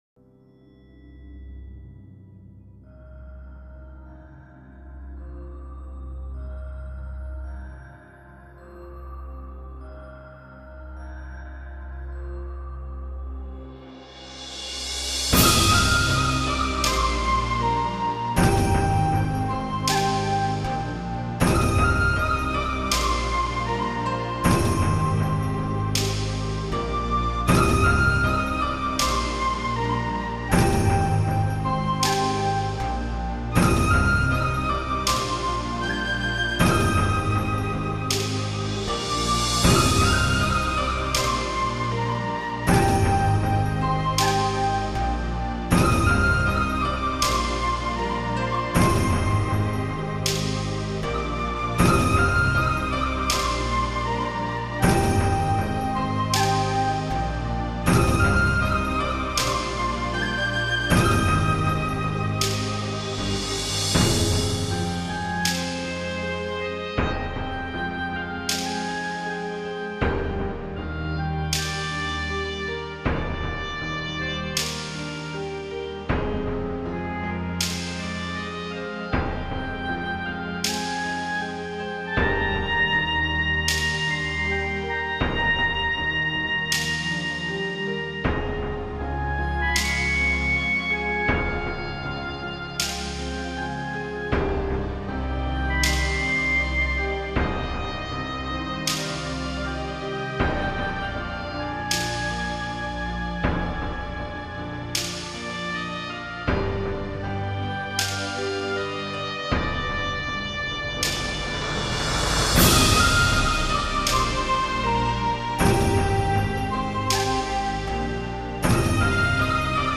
其實這些曲目...是早期霹靂跟天宇所用的人物場景配樂，不過事實上...這些是用很多動畫或是一些其他音樂集改編而來的，